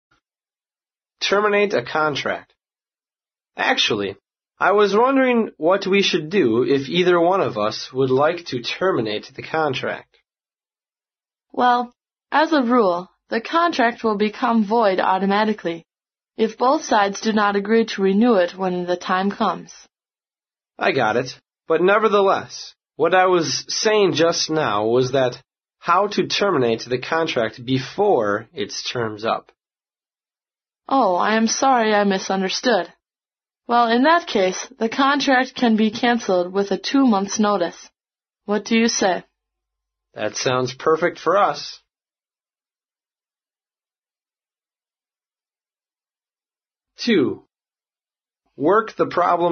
在线英语听力室外贸英语话题王 第76期:合同终止的听力文件下载,《外贸英语话题王》通过经典的英语口语对话内容，学习外贸英语知识，积累外贸英语词汇，潜移默化中培养英语语感。